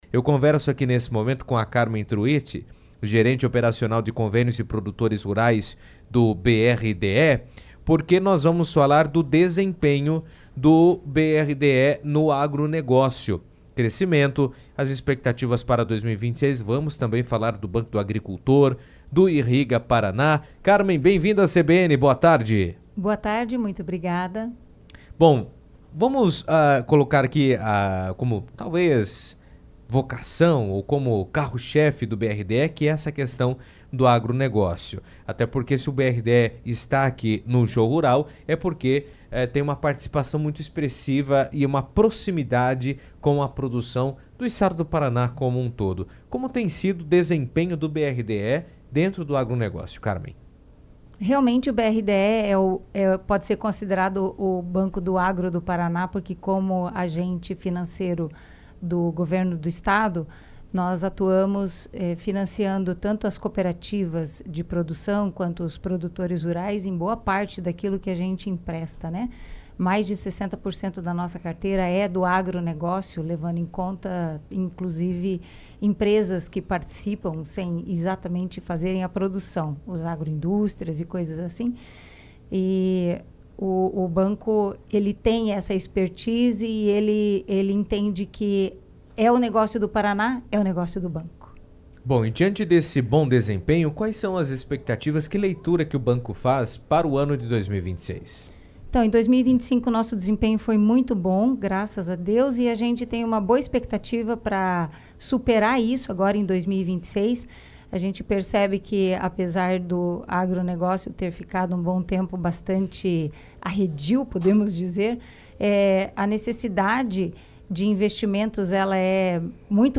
esteve no estúdio da CBN durante a 38ª edição do Show Rural Coopavel e comentou sobre os resultados do banco no setor agropecuário. Ela destacou o crescimento dos contratos, a atuação do Banco do Agricultor e programas como Irriga PR, além de apontar que linhas voltadas a energia, armazenagem, irrigação e pecuária têm apresentado maior demanda.